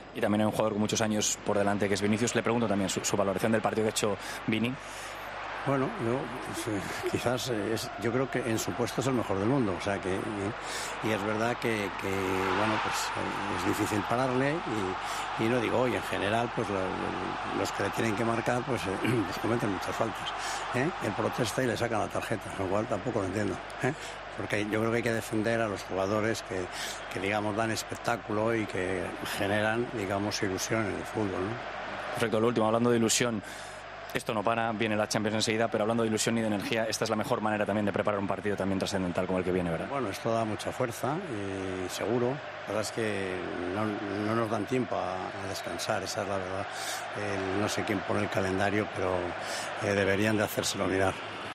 El presidente del Real Madrid habló en TVE tras lograr la 20ª Copa del Rey con dos goles de Rodrygo ante Osasuna.